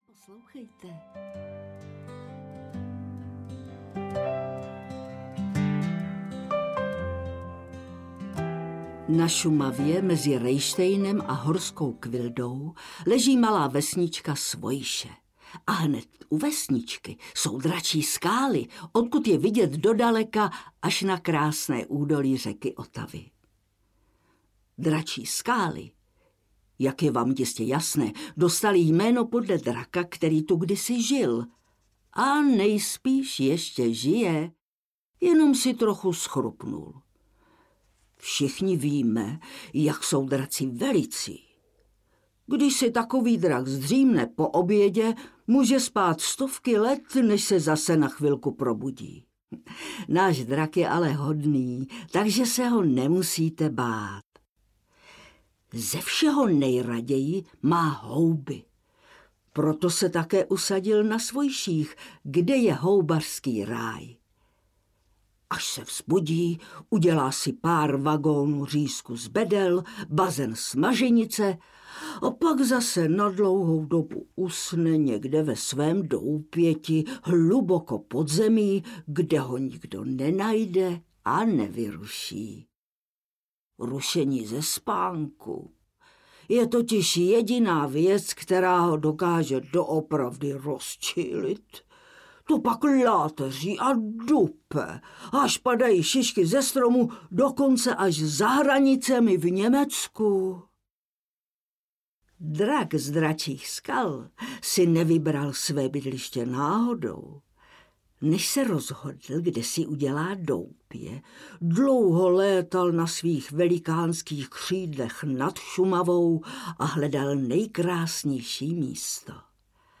Pohádky z Dračí skály audiokniha
Ukázka z knihy